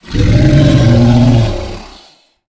Sfx_creature_snowstalker_distantcall_05.ogg